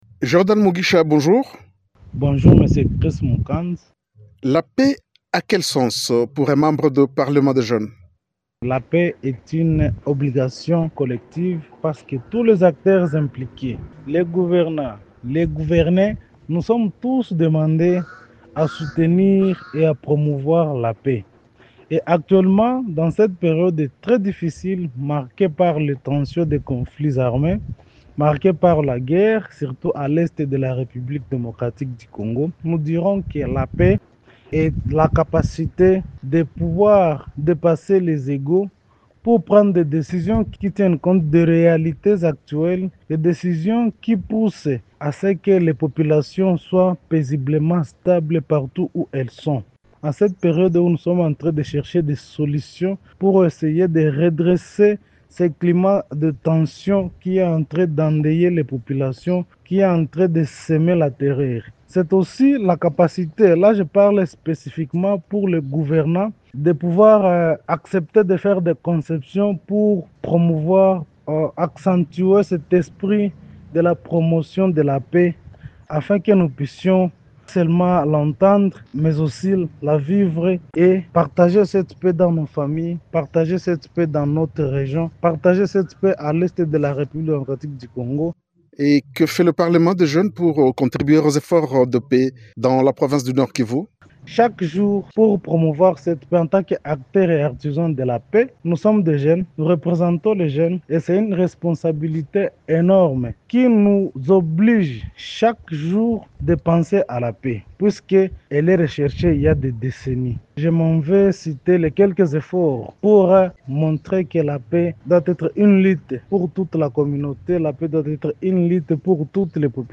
Dans une interview accordée à Radio Okapi, il a insisté sur l’implication nécessaire de tous les citoyens, en particulier les jeunes, dans la construction d’un climat pacifique en RDC.